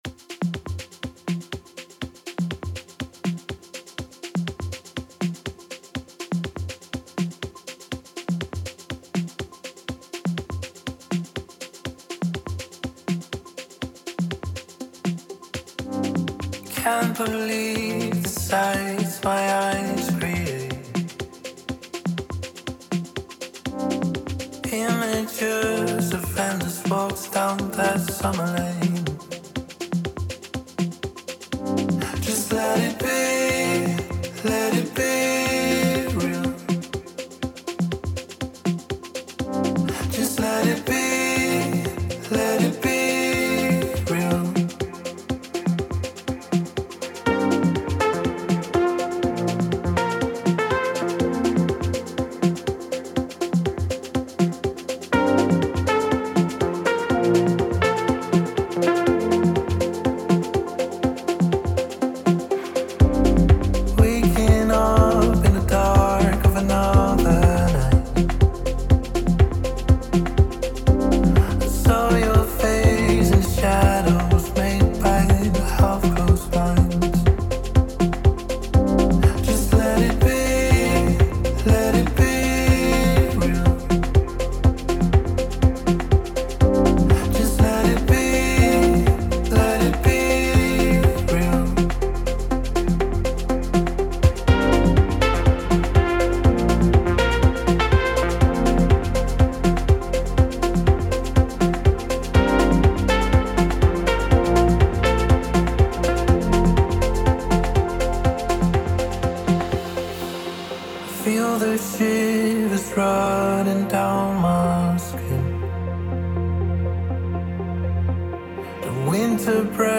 Also find other EDM Livesets, DJ Mixes and Radio
with an incredible live mix from our London HQ